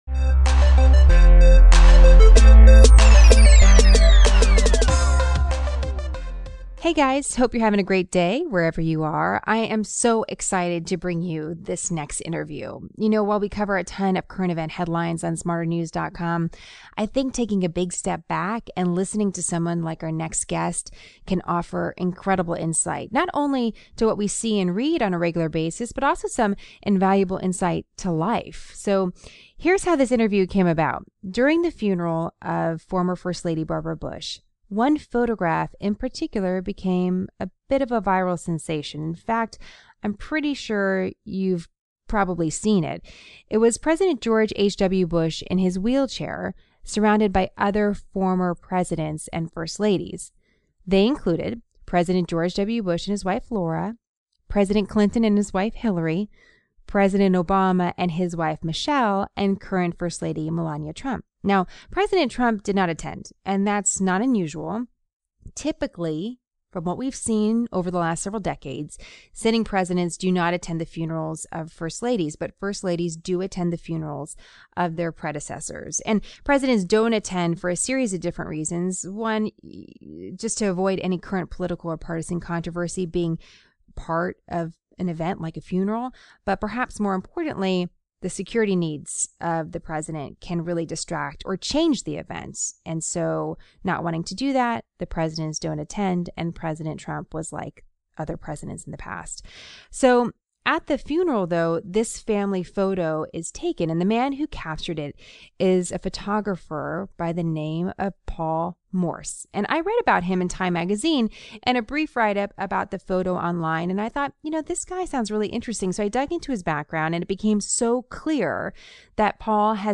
He joins us to talk about his career but more importantly to speak about what happens behind the scenes when in the presence of the most powerful men and women in the world.